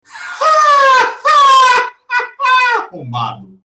Play Risadinha Sarcastica - SoundBoardGuy
Play, download and share Risadinha sarcastica original sound button!!!!